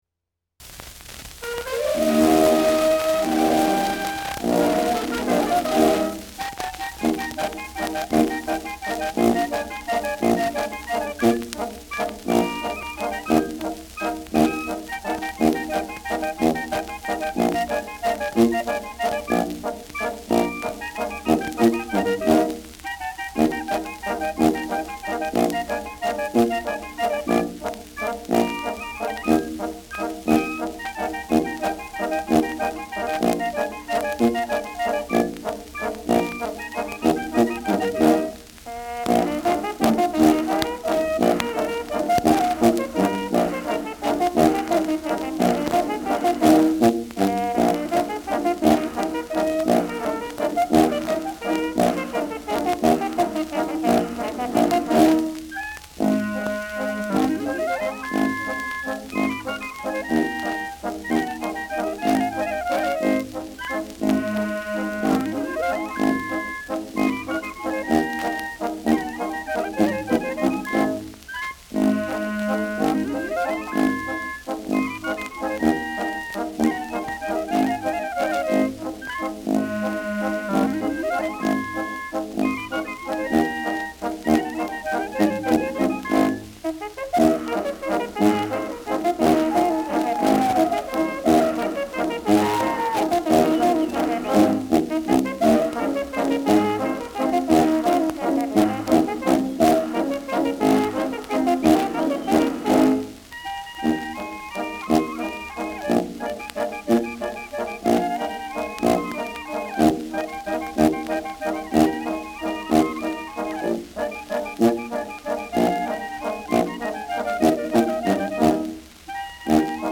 Schellackplatte
präsentes Rauschen : präsentes Knistern : abgespielt : leiert : gelegentliches „Schnarren“
Dachauer Bauernkapelle (Interpretation)
Mit Juchzern.